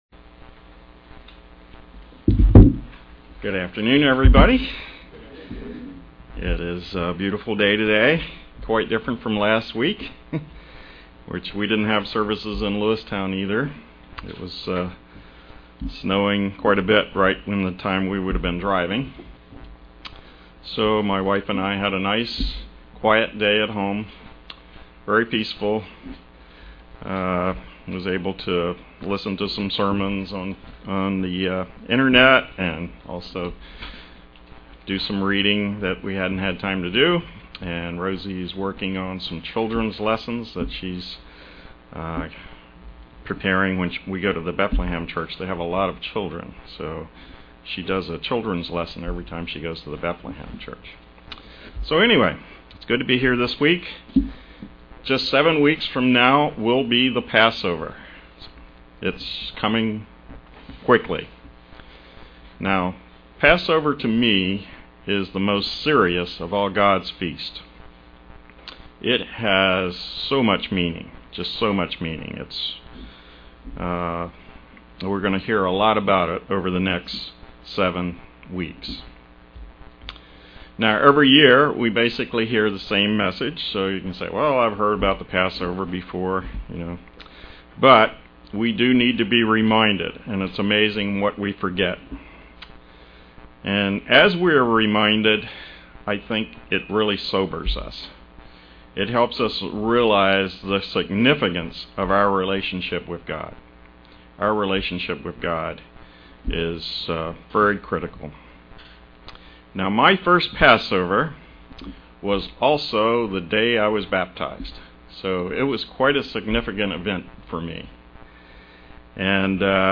Christians are to examine themselves in preparation for Passover. In this sermon are fourteen points to consider as this important observance draws near.